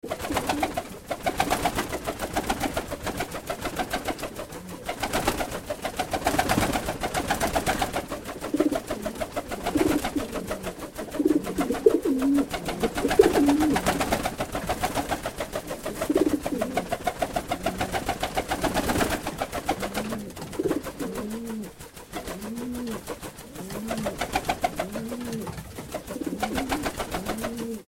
دانلود صدای بال زدن پرنده از ساعد نیوز با لینک مستقیم و کیفیت بالا
جلوه های صوتی